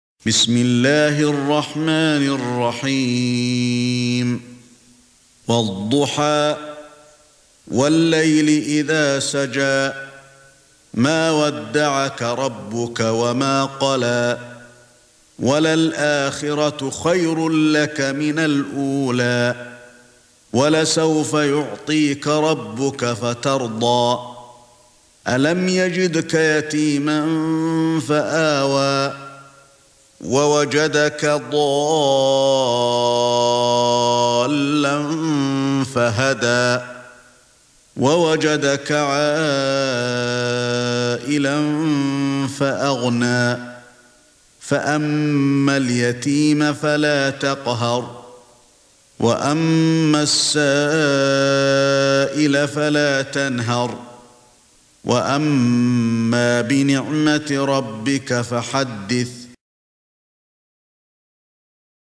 سورة الضحى ( برواية قالون ) > مصحف الشيخ علي الحذيفي ( رواية قالون ) > المصحف - تلاوات الحرمين